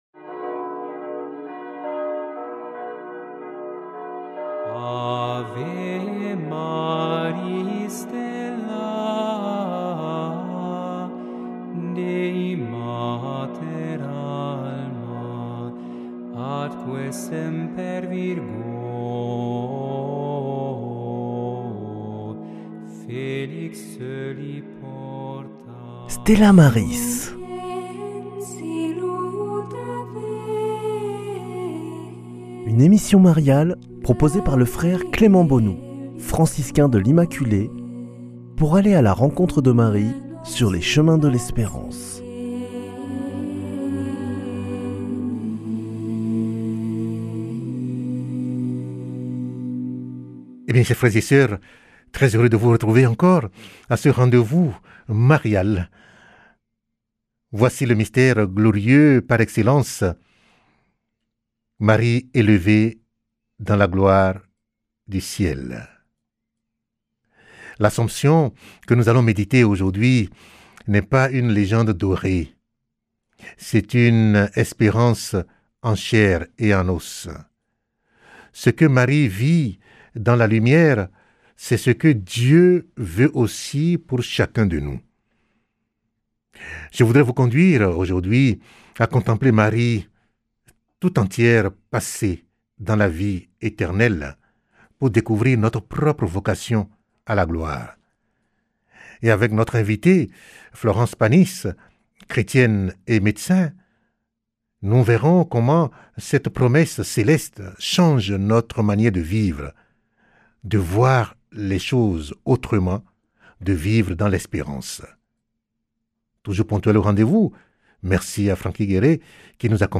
Loin d’être éloignée, elle devient guide, espérance, mère proche pour chacun. Une méditation profonde pour découvrir que nous sommes, nous aussi, appelés à la lumière éternelle.